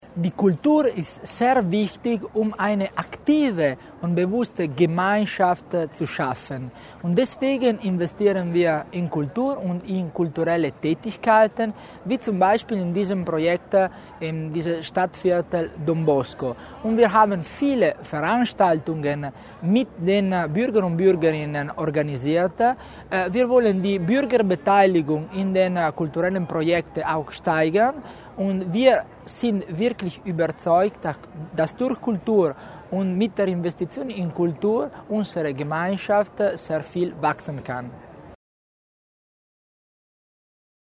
Landesrat Tommasini über die guten Ergebnisse des Projekts